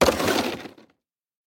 kickBlock.ogg